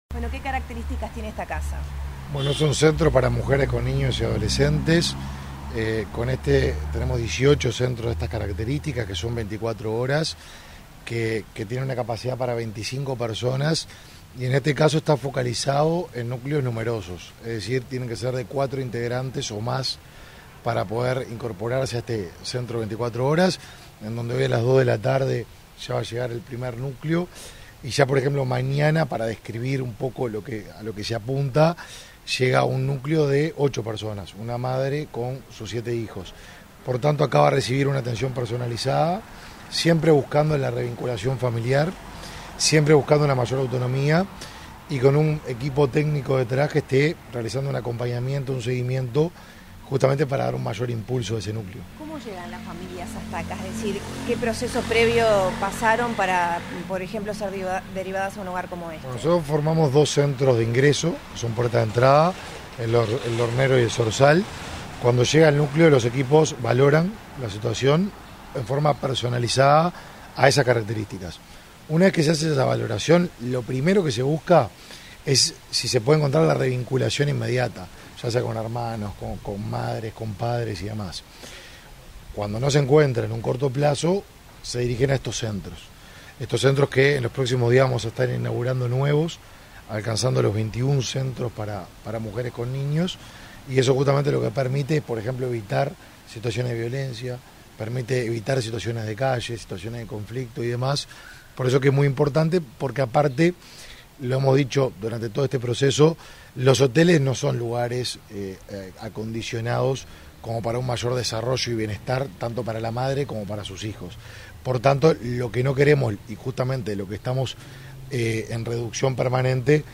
Declaraciones del ministro de Desarrollo Social, Martín Lema
Declaraciones del ministro de Desarrollo Social, Martín Lema 16/02/2023 Compartir Facebook X Copiar enlace WhatsApp LinkedIn Tras la inauguración de un centro 24 horas para familias monoparentales, este 16 de febrero, el ministro de Desarrollo Social, Martín Lema, realizó declaraciones a la prensa.